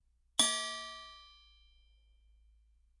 描述：鸡尾酒调酒器与茶匙坠毁。图（Staccato）
Tag: Tascam的 贝尔 DR-05